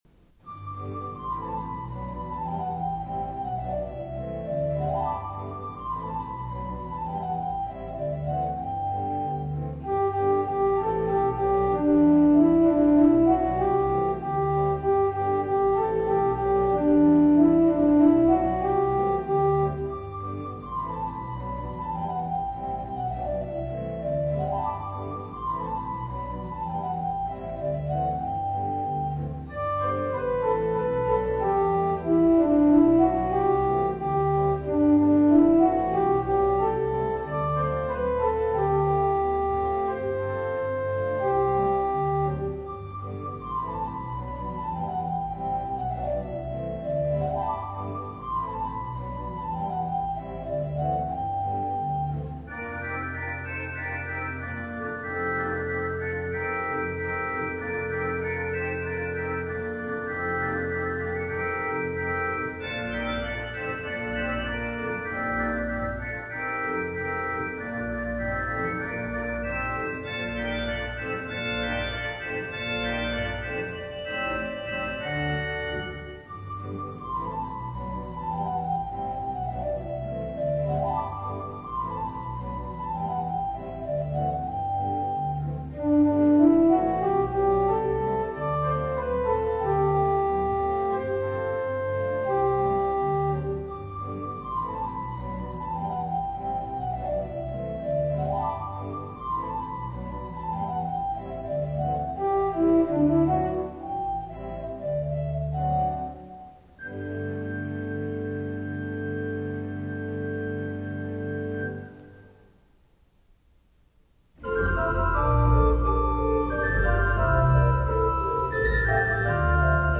Voicing: Organ Solo